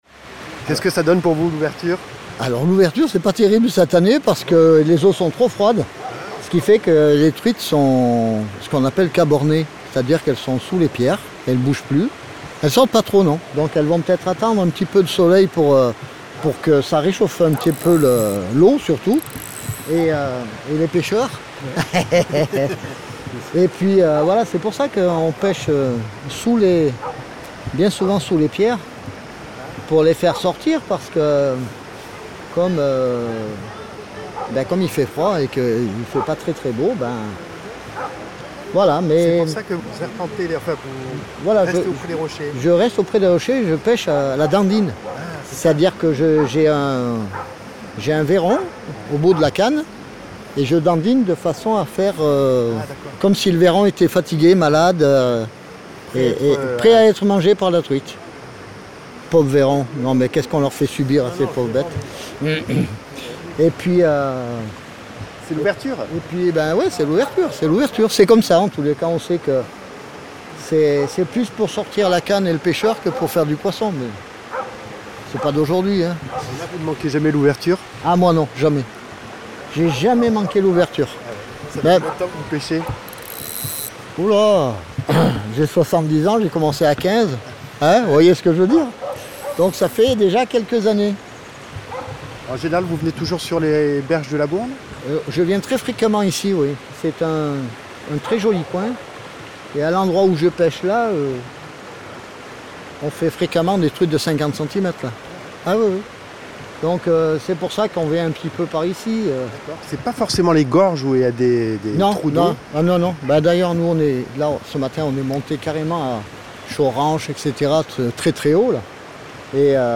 Tranquille ouverture de la pêche 2025 ce samedi 8 mars en bord de Bourne à Pont en Royans par un beau soleil, laissant entrevoir et apprécier une certaine ambiance de printemps.
Un pêcheur, fidèle à la tradition de « faire l’ouverture » nous entretient sur cette passion au fil de l’eau…et du temps.